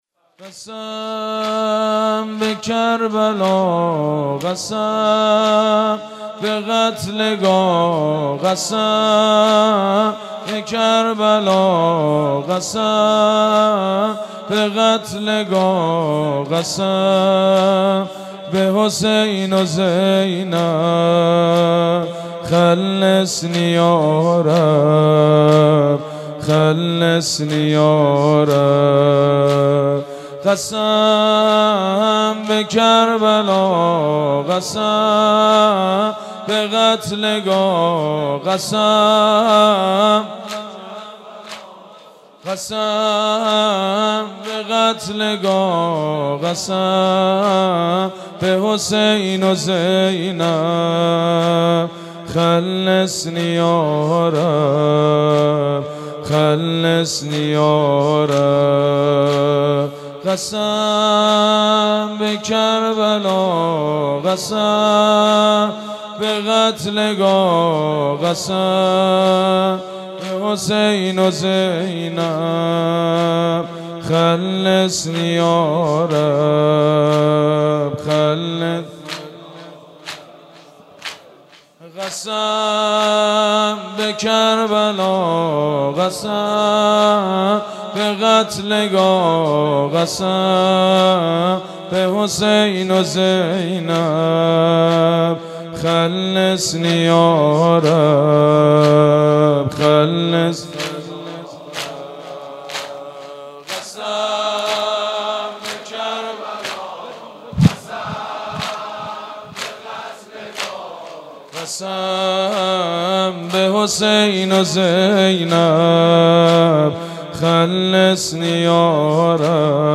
مناسبت : شهادت امام موسی‌کاظم علیه‌السلام
مداح : سیدمجید بنی‌فاطمه قالب : زمینه